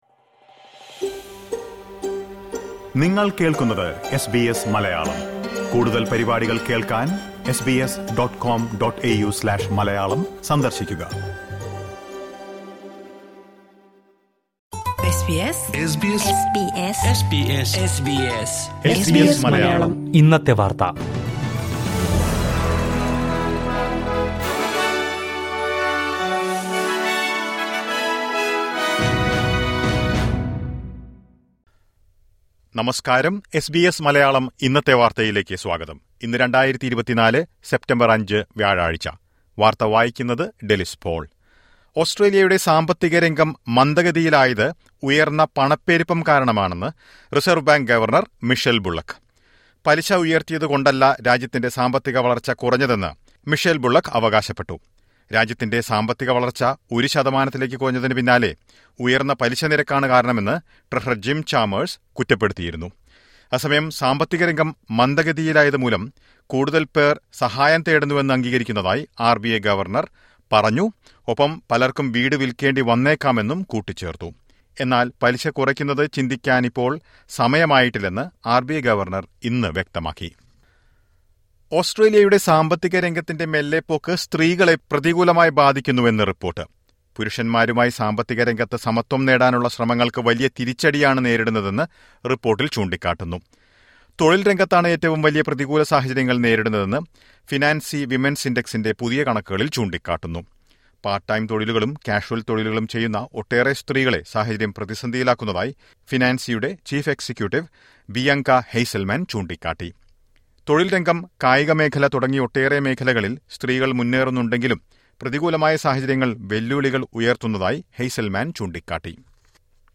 2024 സെപ്റ്റംബര്‍ അഞ്ചിലെ ഓസ്‌ട്രേലിയയിലെ ഏറ്റവും പ്രധാന വാര്‍ത്തകള്‍ കേള്‍ക്കാം...